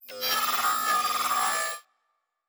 Sci-Fi Sounds / Electric / Data Calculating 5_3.wav
Data Calculating 5_3.wav